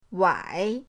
wai3.mp3